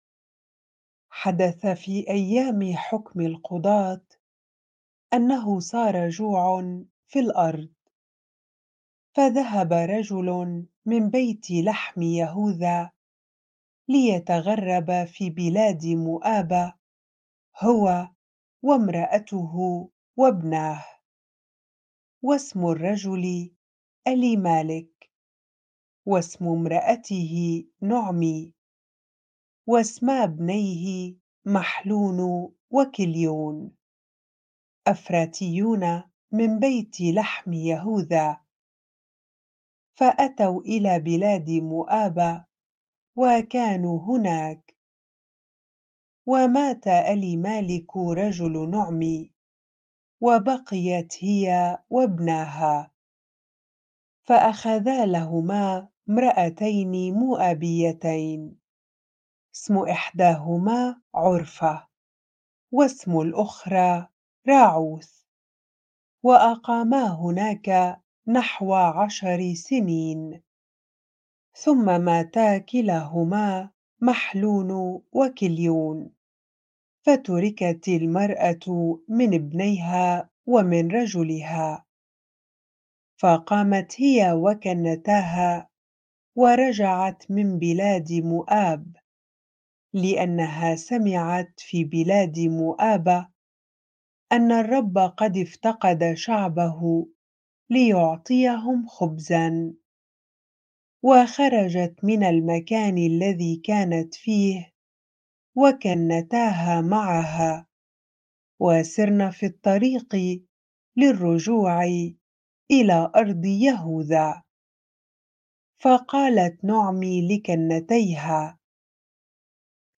bible-reading-Ruth 1 ar